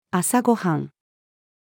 朝ご飯-female.mp3